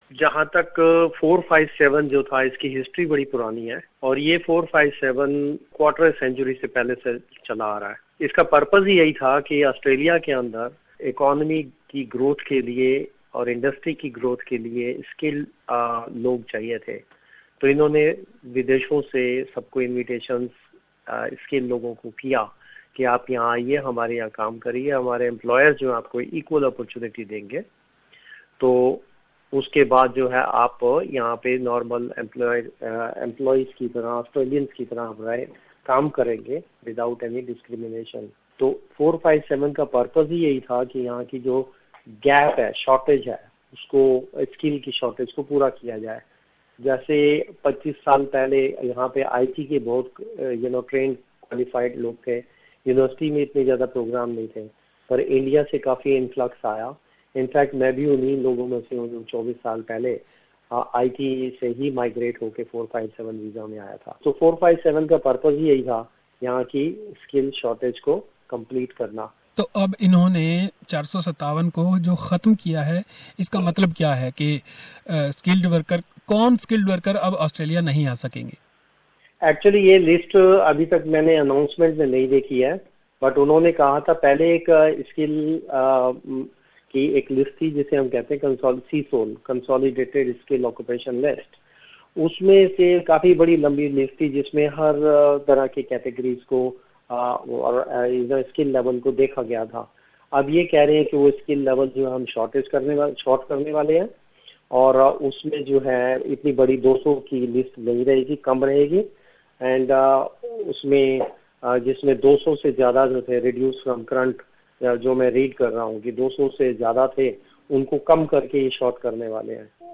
Australia's Prime Minister Malcolm Turnbull has announced he is abolishing the 457 visa program for skilled migrants. How will Indians be affected by this decision? Listen to the interview